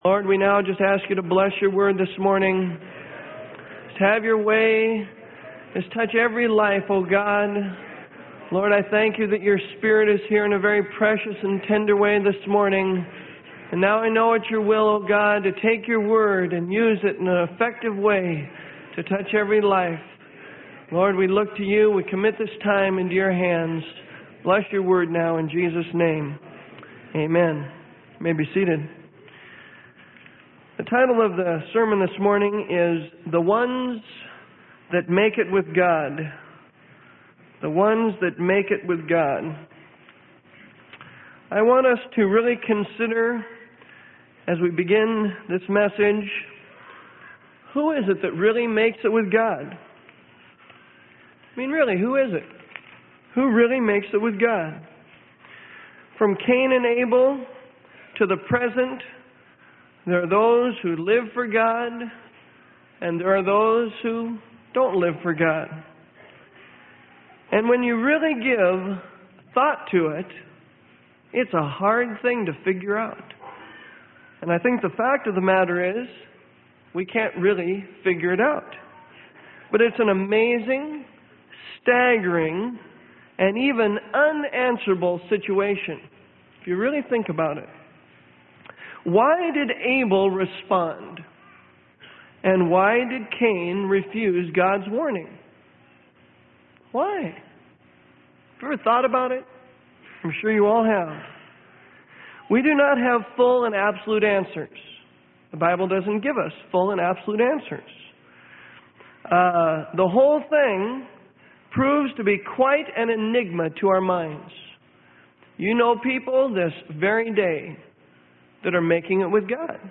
Sermon: THE ONES THAT MAKE IT WITH GOD - Freely Given Online Library